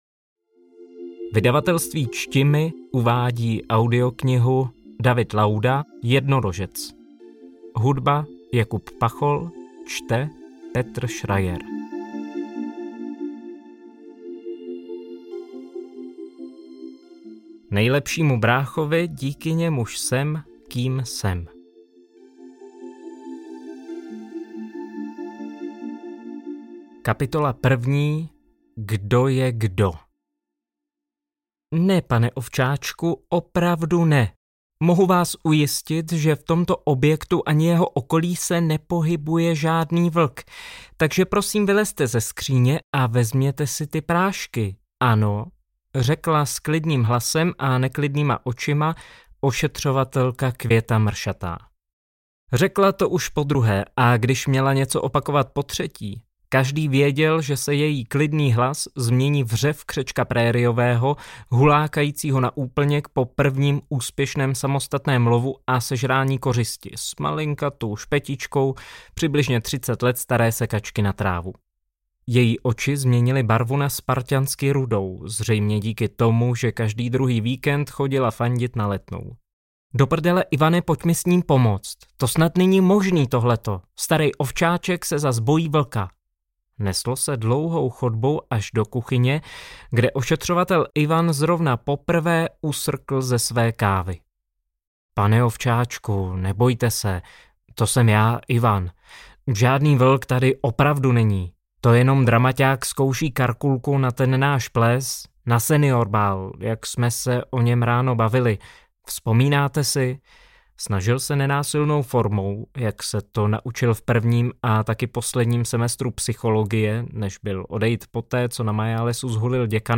Kategorie: Román